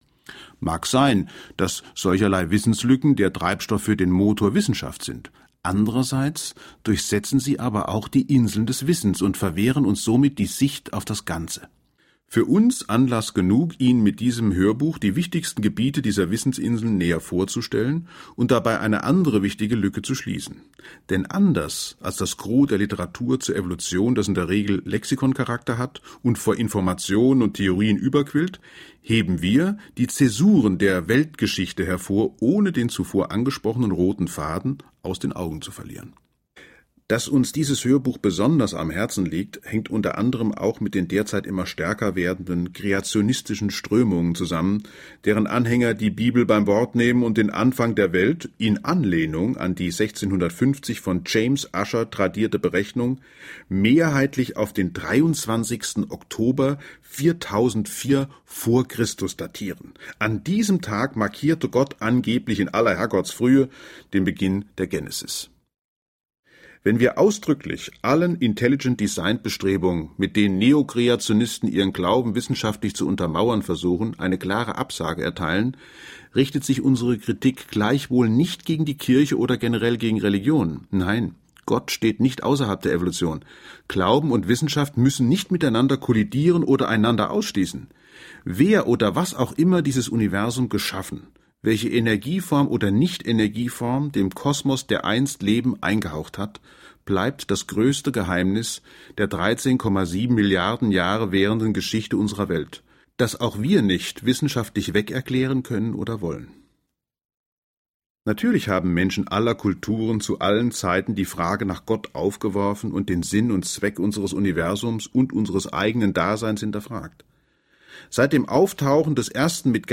Eine Reportage über 13,7 Milliarden Jahre Werden und Vergehen